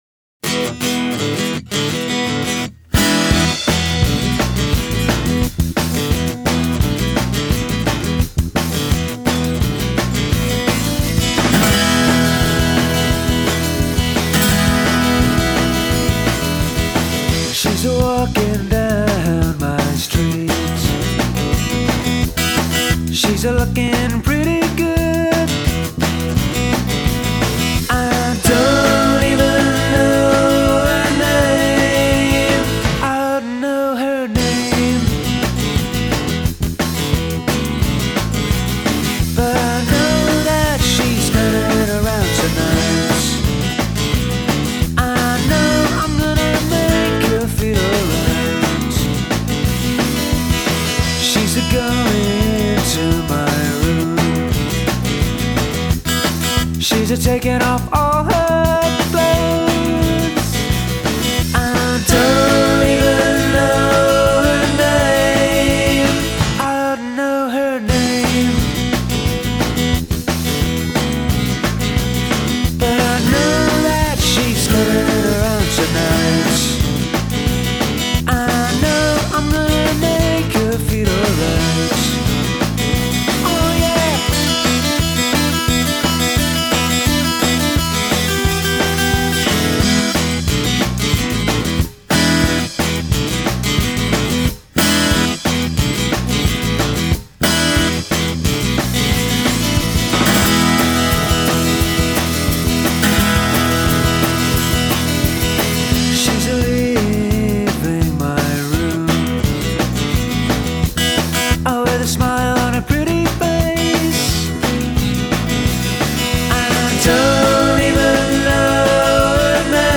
make their acoustic guitars jump out of the speakers